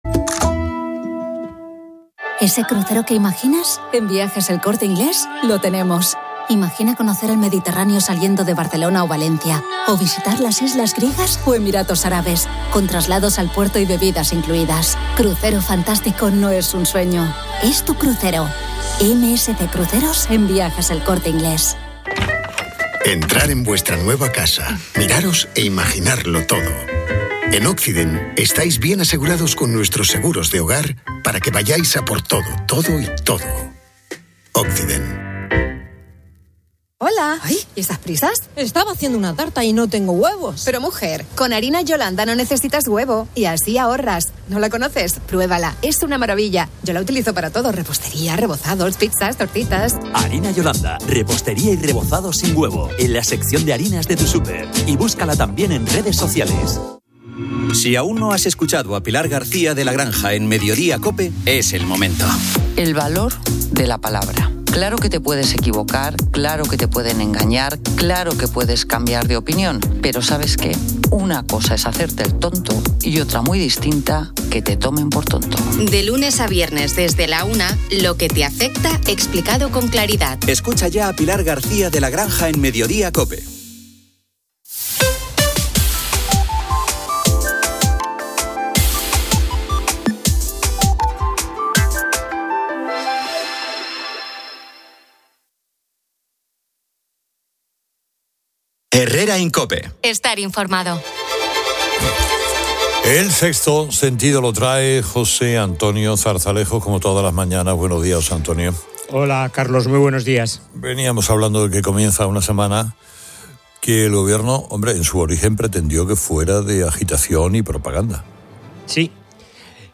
El programa también presenta una entrevista con César Antonio Molina, autor de "Insurgentes", quien reflexiona sobre el papel del intelectual en la política y la dificultad de la derecha para comprender la cultura.